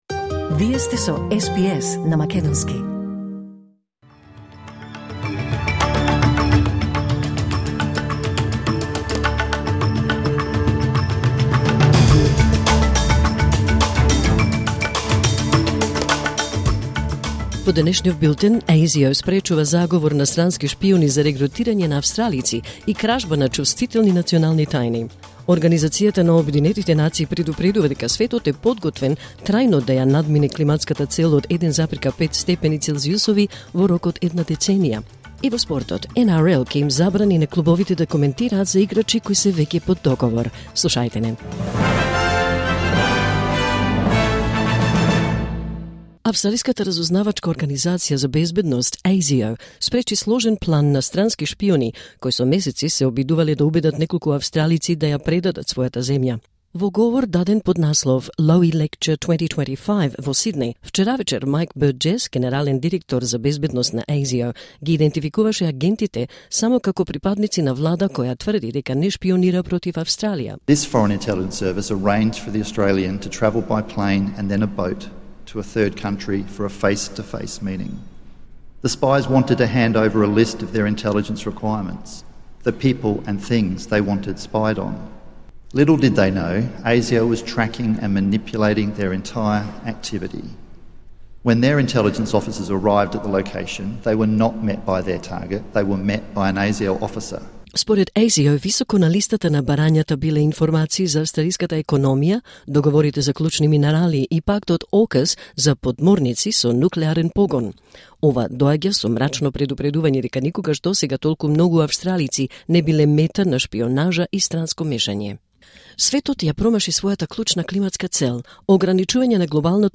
Вести на СБС на македонски 5 ноември 2025